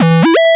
retro_synth_beeps_08.wav